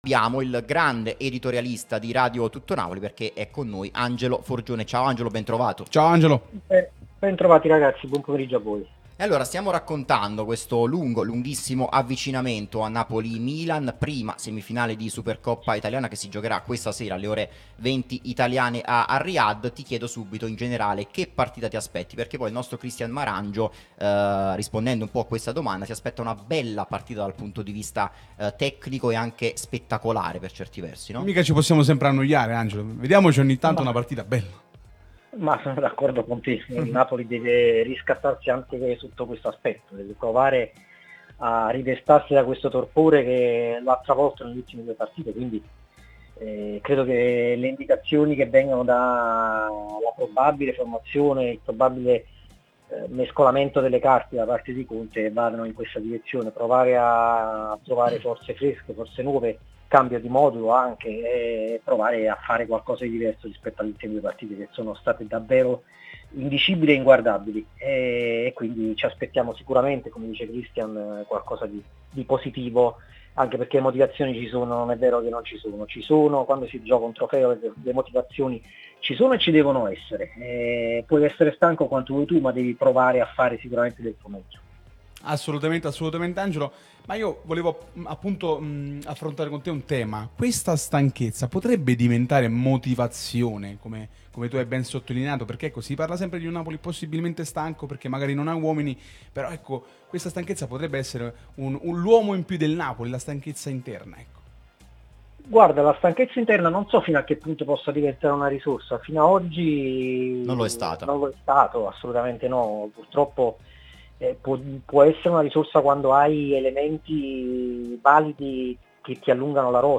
giornalista e scrittore, è intervenuto nel corso di 'Napoli Talk', trasmissione sulla nostra Radio Tutto Napoli